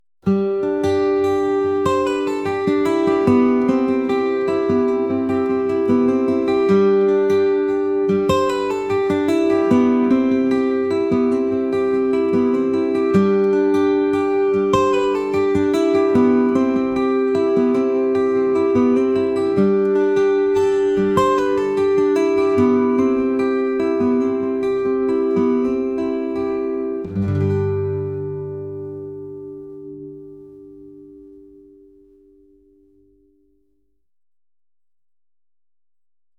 folk | acoustic | ambient